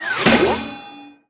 safe_drop_01.wav